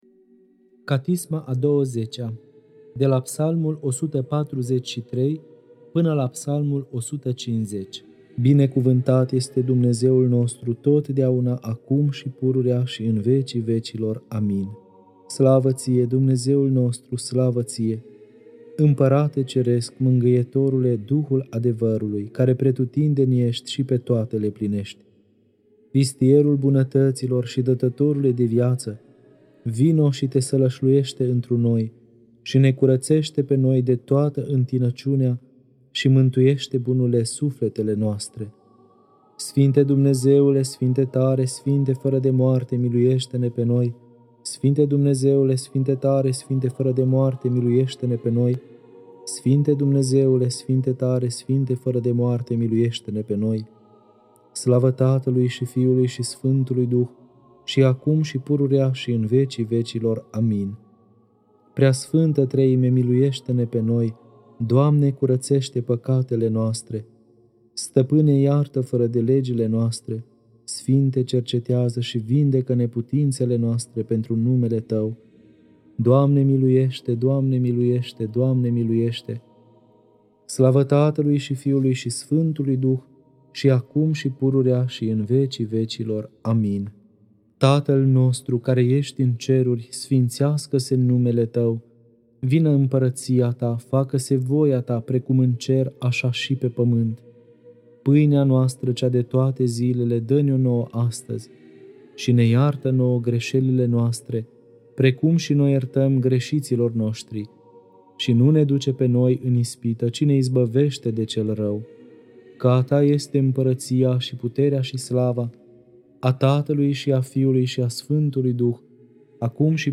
Catisma a XX-a (Psalmii 143-150) Lectura